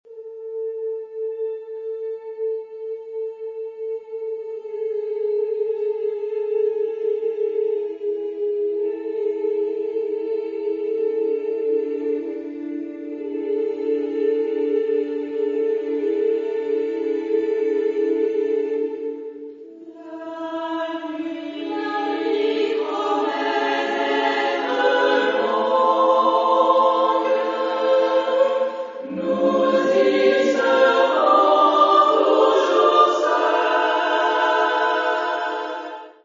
Genre-Style-Forme : Profane ; Poème ; contemporain
Type de choeur : SSAA  (4 voix égales OU égales de femmes )
Tonalité : polymodal